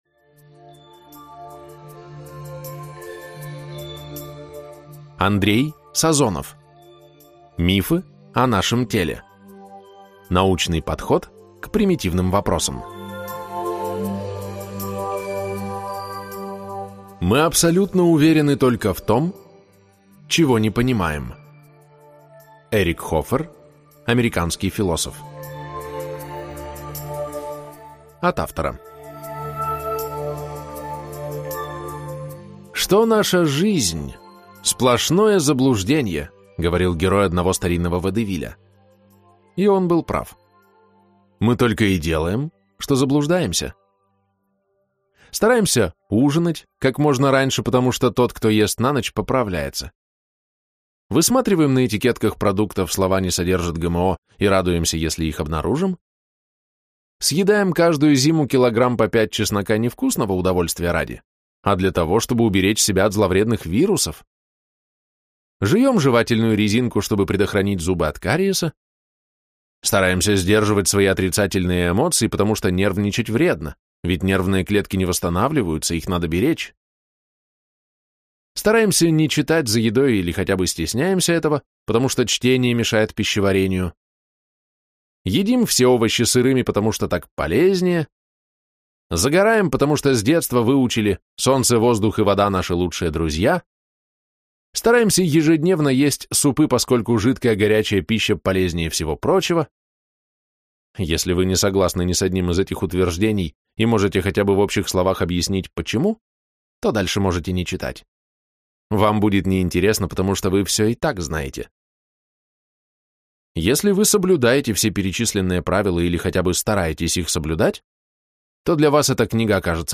Прослушать фрагмент аудиокниги Мифы о нашем теле.